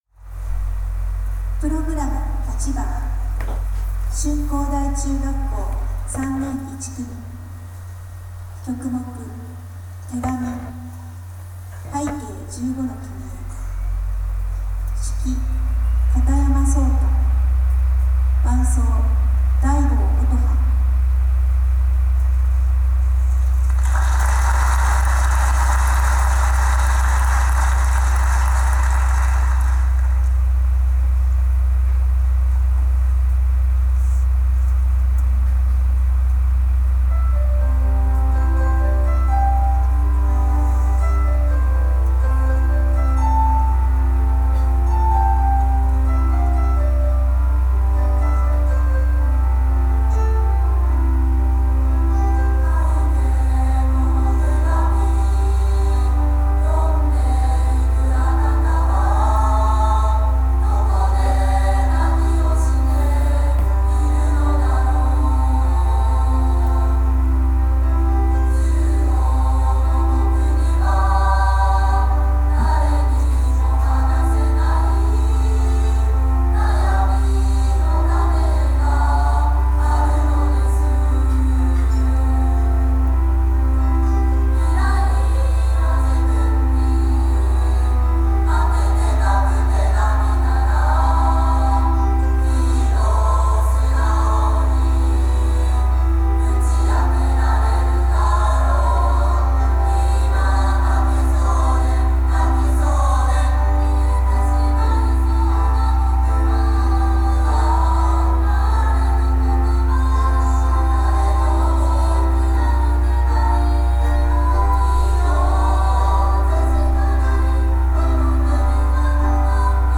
小中音楽発表会！
１０月５日（金），旭川市の小中学校が参加する小中学校音楽発表会が，市民文化会館を会場に行われました。
３年１組の諸君，本校の代表として素敵な合唱を披露してくれてありがとう。
下に合唱曲をmp3ファイルで貼り付けておきます。